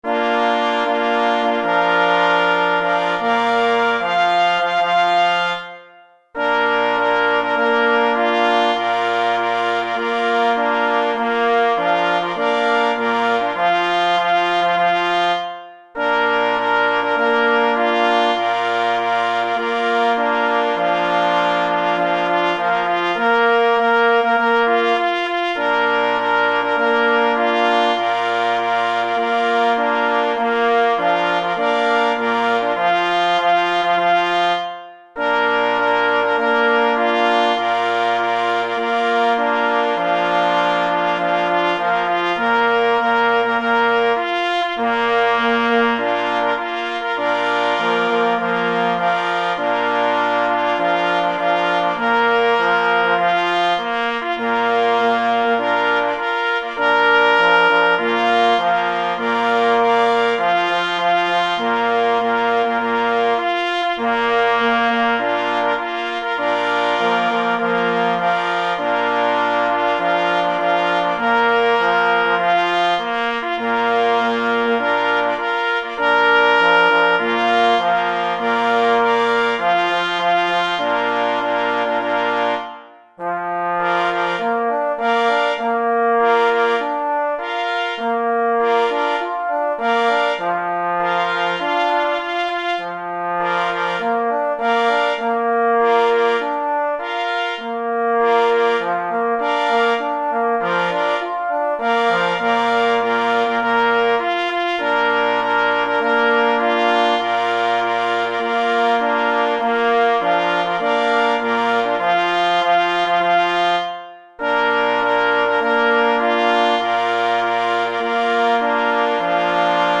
Opis zasobu: marsz myśliwski na 3 plesy i […]